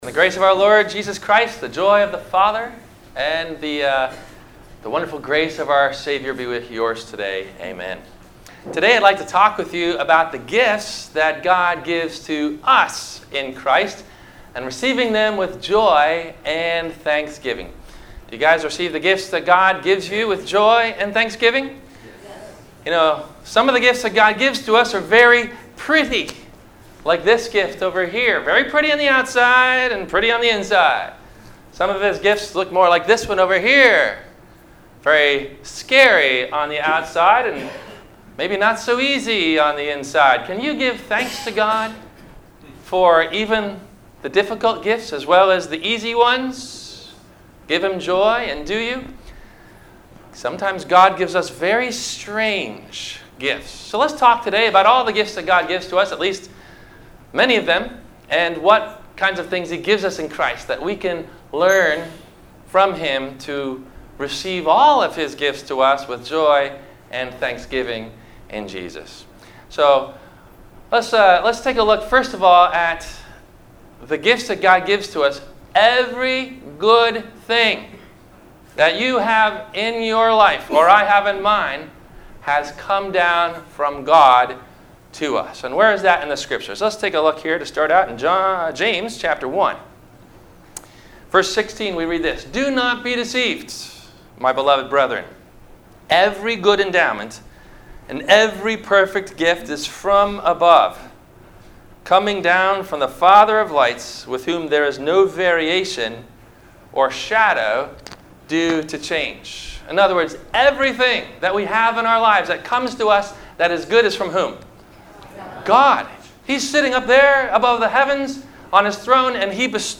- Sermon - December 30 2018 - Christ Lutheran Cape Canaveral